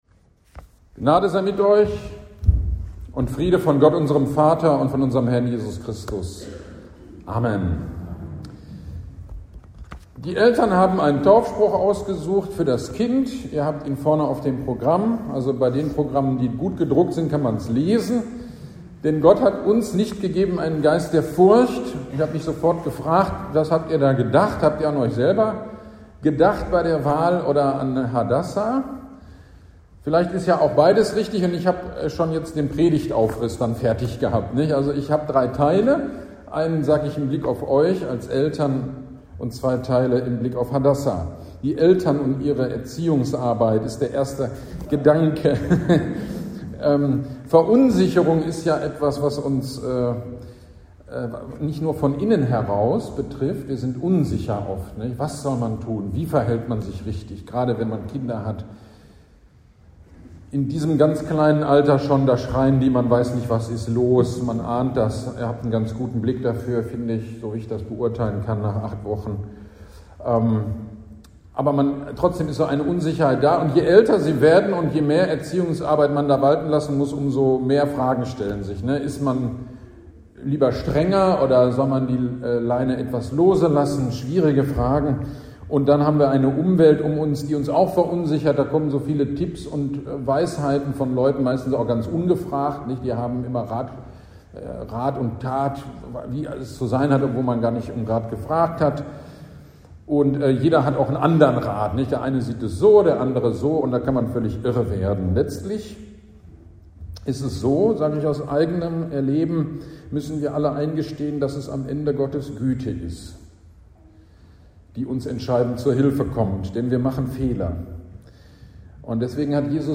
GD am 20.08.23 Taufpredigt zu 2. Timotheus 1.7 - Kirchgemeinde Pölzig